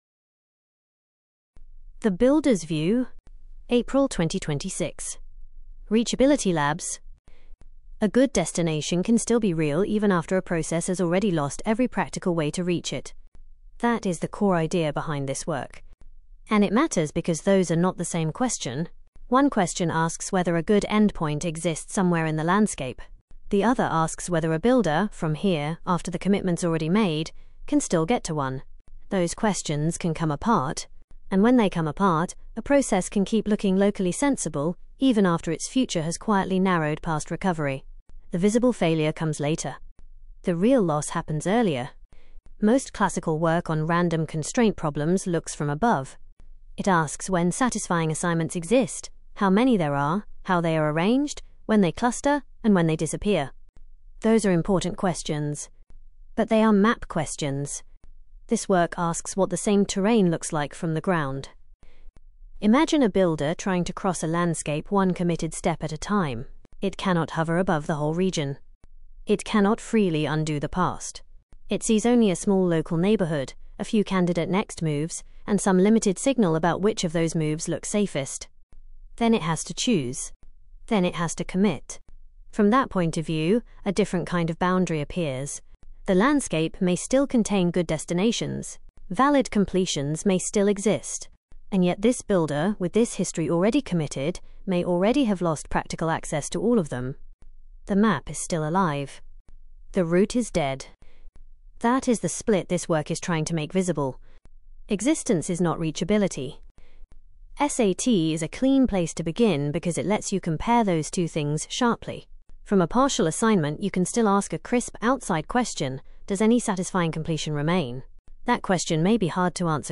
spoken narration